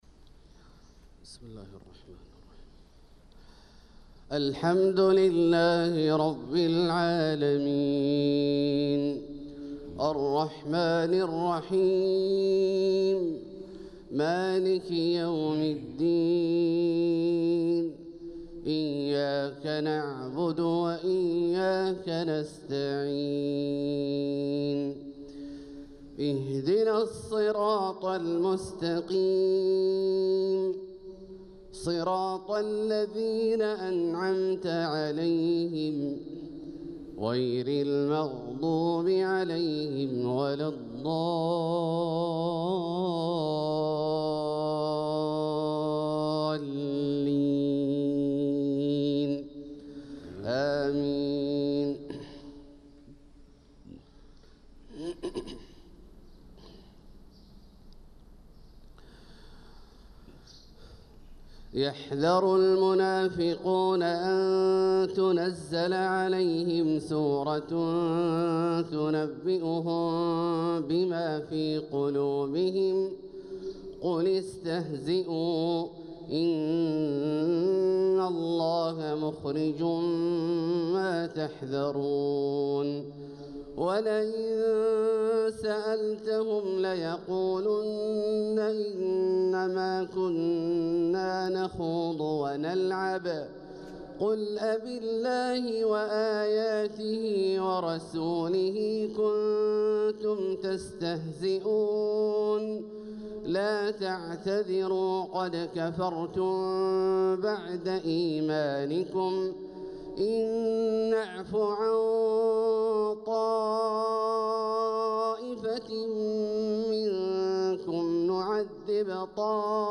صلاة الفجر للقارئ عبدالله الجهني 8 صفر 1446 هـ